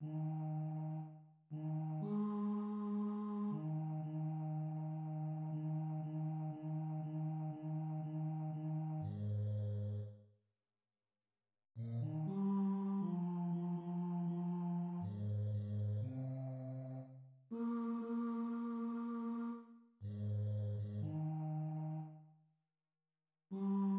{ \new Staff \with {midiInstrument = #"voice oohs"}{\clef bass \tempo 4 = 120 \key ees \major \numericTimeSignature \time 2/2 ees2 r4 ees\time 4/2 aes2. ees4 ees2. ees4 \time 3/2 ees4 ees ees ees ees ees \time 4/2 aes,2 r2 r4 r8 bes,8 ees8 g4.